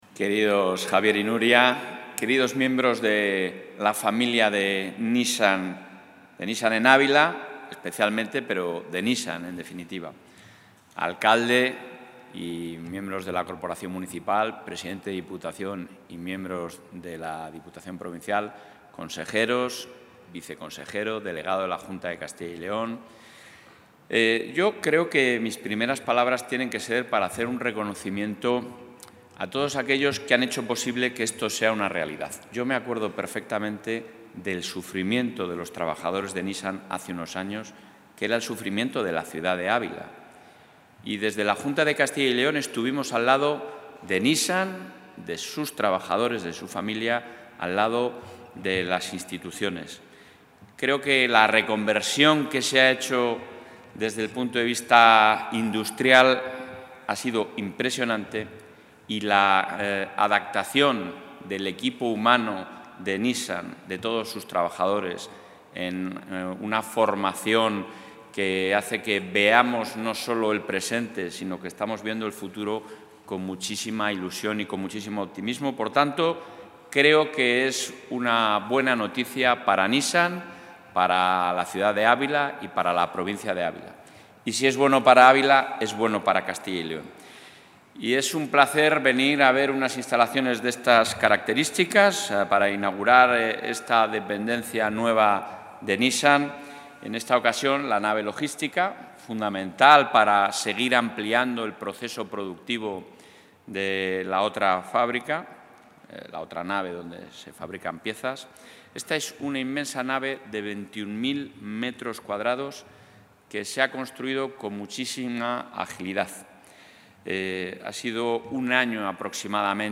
Intervención del presidente de la Junta.
El presidente de la Junta de Castilla y León, Alfonso Fernández Mañueco, ha visitado el nuevo almacén logístico que el Gobierno autonómico ha entregado a Nissan Ávila, donde ha destacado el liderazgo de la Comunidad en el crecimiento de las exportaciones en 2023, con un aumento superior al 15 % interanual y de más del 10 % en la provincia de Ávila en el periodo enero-noviembre, frente al descenso nacional. Un liderazgo que, como ha indicado, se ha visto impulsado sobre todo por la automoción, cuyas ventas al exterior han repuntado un 42 %.